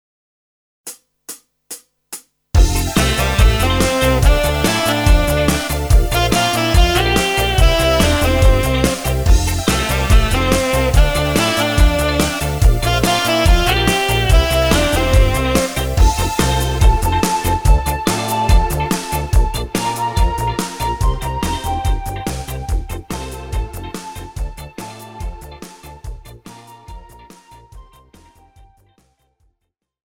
Žánr: Pop
BPM: 143
Key: F
MP3 ukázka
MP3 s melo. linkou